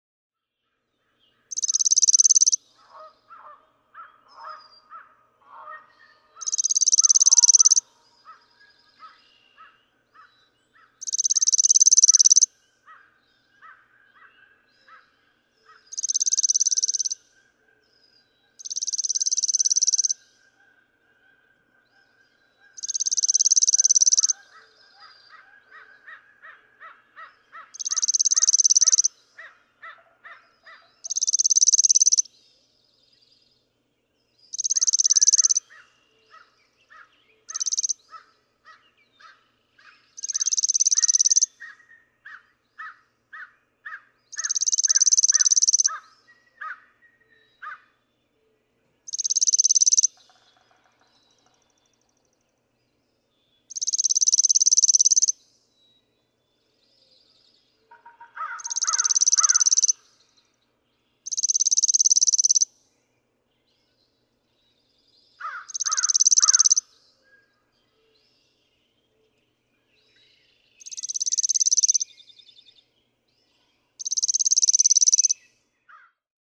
Chipping sparrow
Garland Lodge and Golf Resort, Lewiston, Michigan.
♫192, ♫193—longer recordings from those two neighbors
193_Chipping_Sparrow.mp3